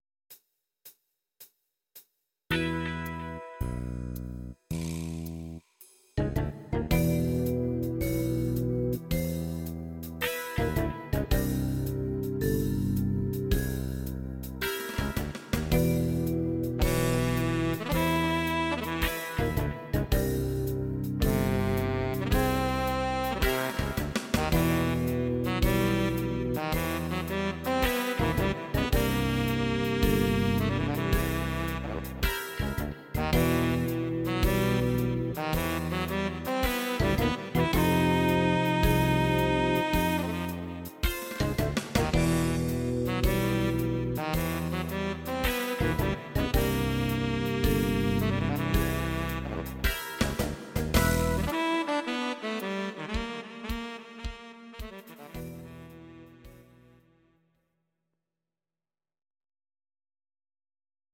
These are MP3 versions of our MIDI file catalogue.
Please note: no vocals and no karaoke included.
instr. Saxophon